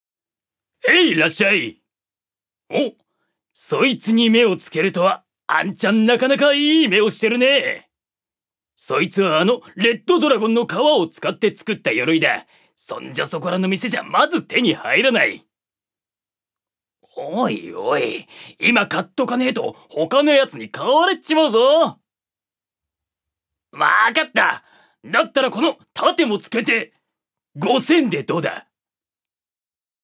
預かり：男性
セリフ４